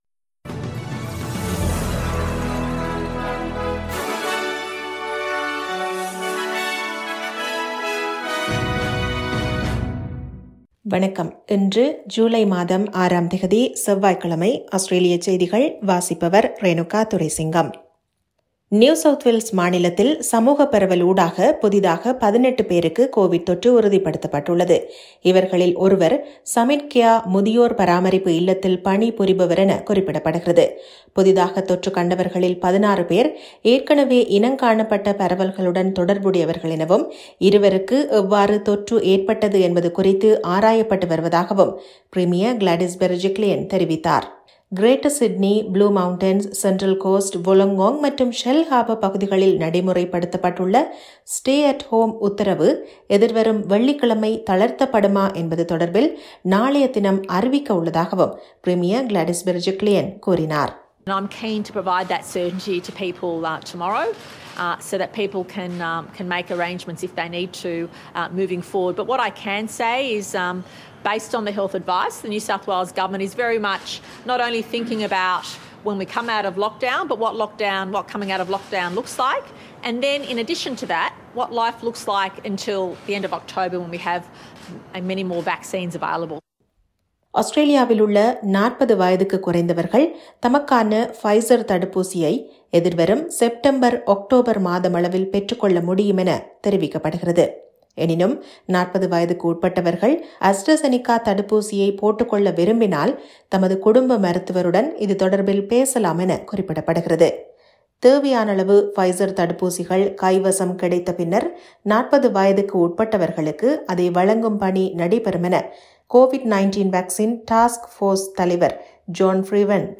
SBS தமிழ் ஒலிபரப்பின் இன்றைய செவ்வாய்க்கிழமை ( 06/07/2021) ஆஸ்திரேலியா குறித்த செய்திகள்.